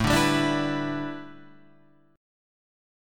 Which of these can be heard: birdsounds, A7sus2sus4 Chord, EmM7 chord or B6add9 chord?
A7sus2sus4 Chord